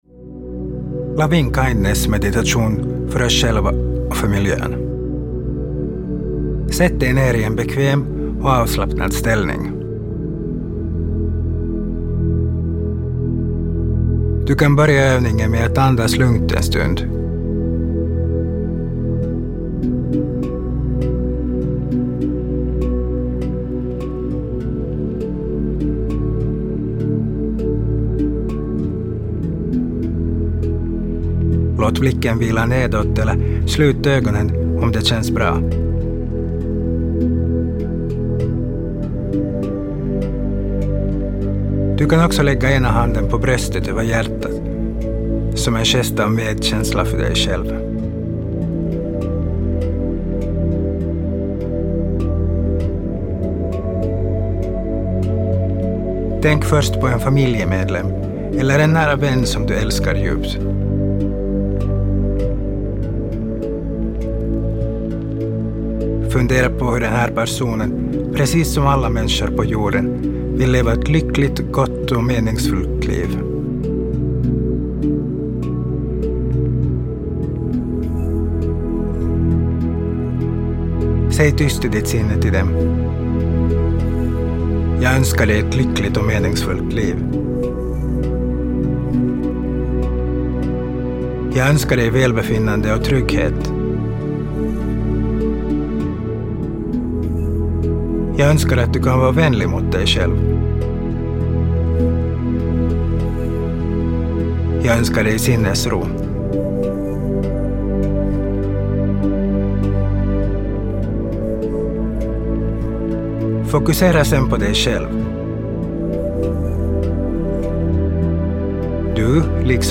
Meditation på svenska